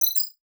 Simple Digital Connection 4.wav